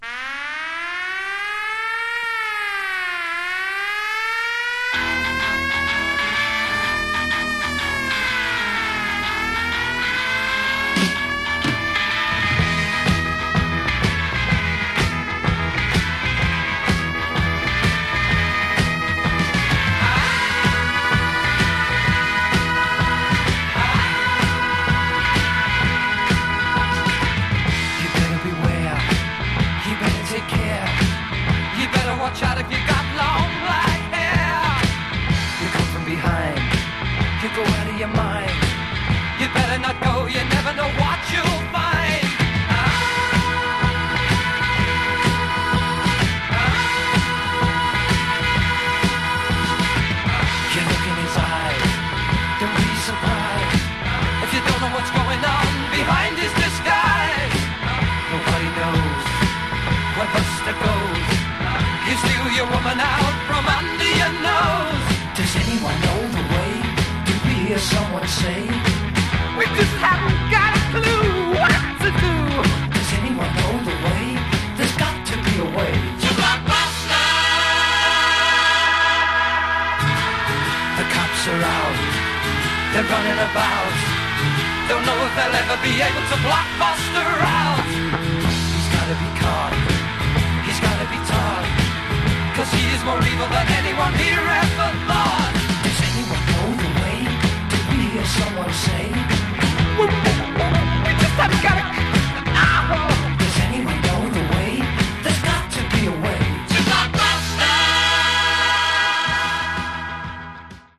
Genre: Bubblegum